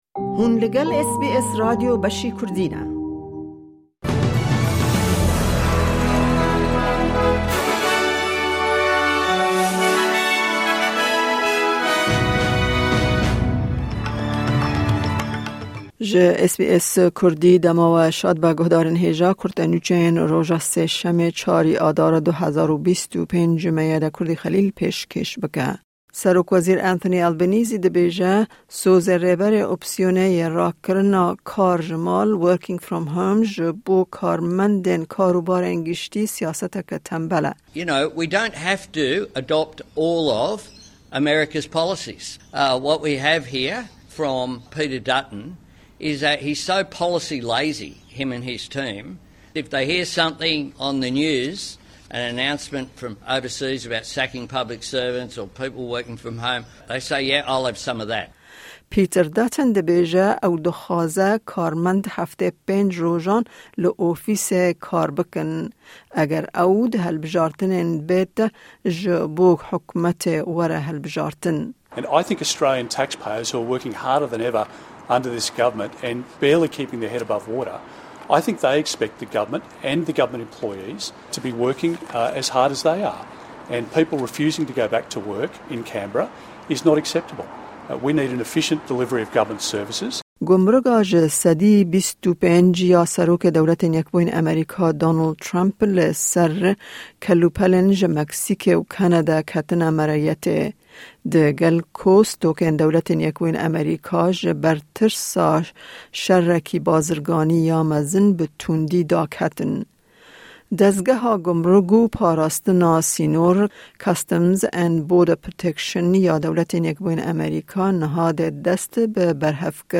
Kurte Nûçeyên roja Sêşemê, 04/03/2025